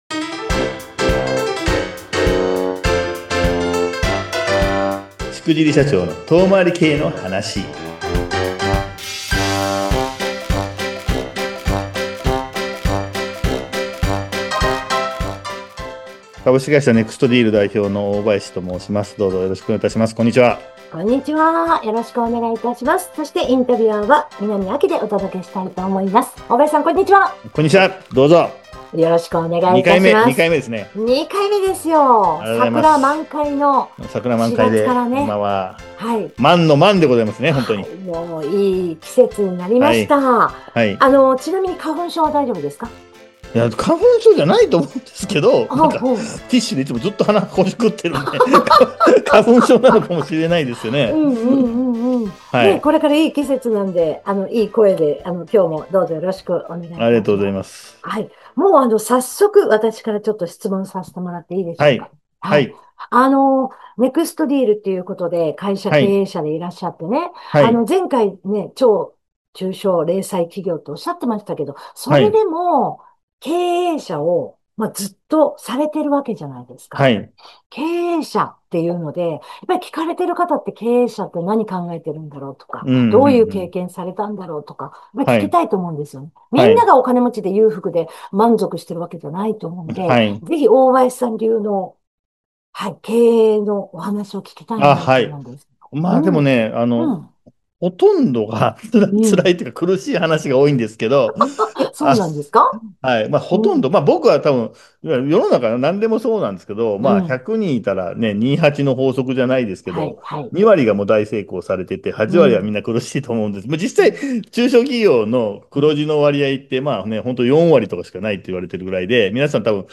〜40・50・60代の経営者に届ける、おっさん経営者のゆるラジオ〜